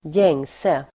Uttal: [²j'eng:se]